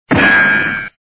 bosscharge.wav